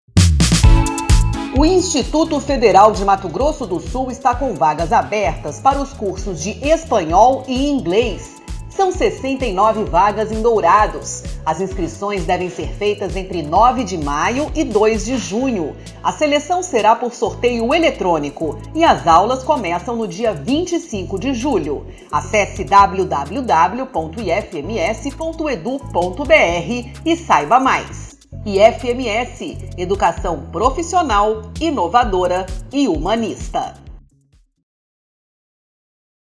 Spot - Cursos de idiomas para o 2º semestre de 2022 em Dourados